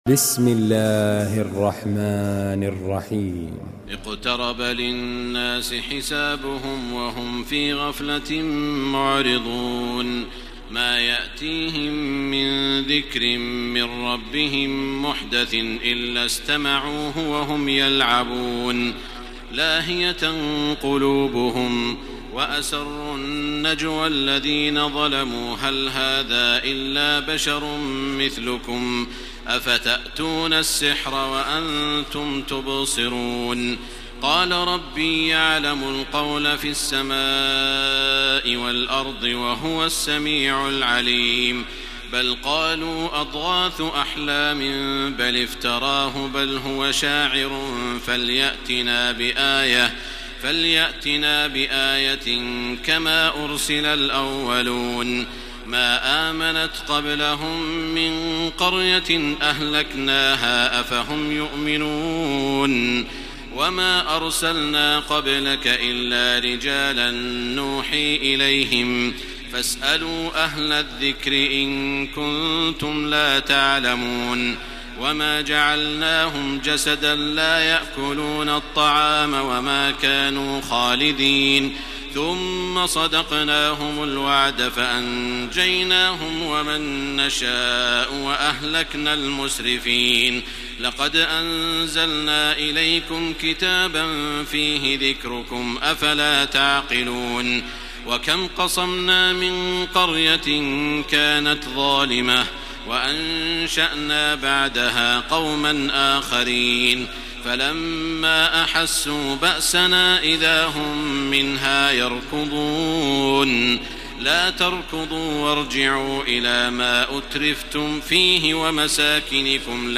تراويح الليلة السادسة عشر رمضان 1433هـ سورة الأنبياء كاملة Taraweeh 16 st night Ramadan 1433H from Surah Al-Anbiyaa > تراويح الحرم المكي عام 1433 🕋 > التراويح - تلاوات الحرمين